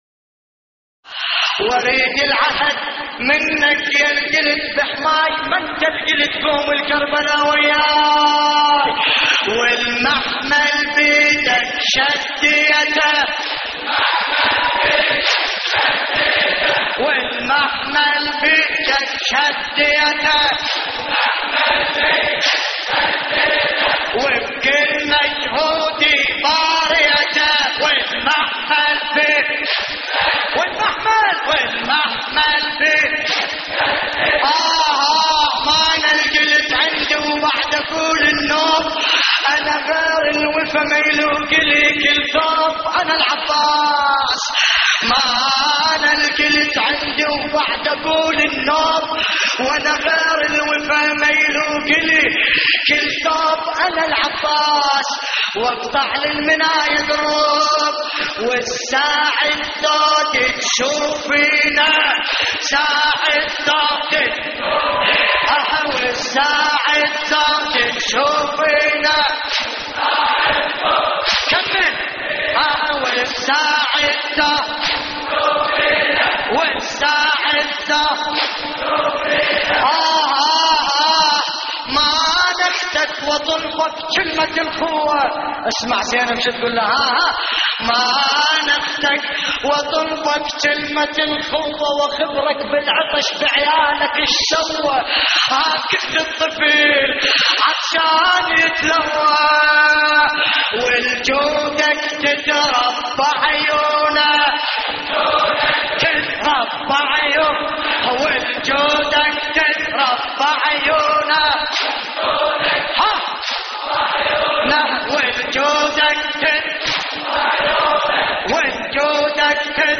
هوسات